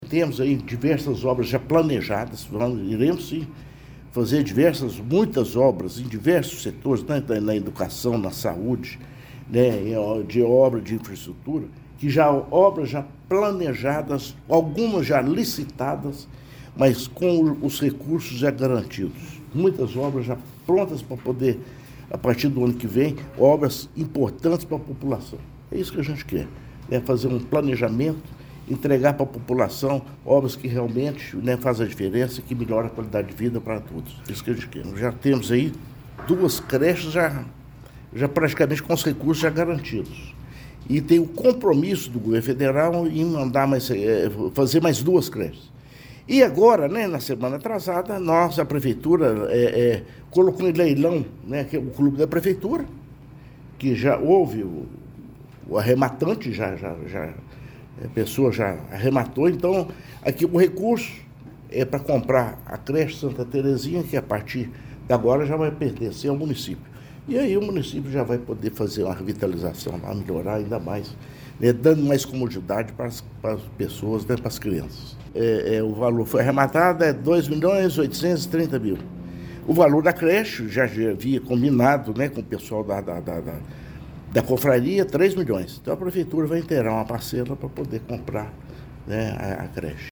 A Prefeitura de Pará de Minas apresentou, nesta segunda-feira, 22 de dezembro, um panorama das principais ações desenvolvidas ao longo de 2025, marcando o primeiro ano da atual administração.
Ao falar sobre o próximo ano, Inácio Franco afirmou que 2026 será um período de continuidade e ampliação dos projetos em andamento: